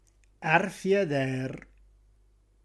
Qui di seguito il nostro repertorio delle parole “reggiane” proprie del nostro dialetto, sia per vocabolo che per significato ad esso attribuito, corredate della traccia audio con la dizione dialettale corretta.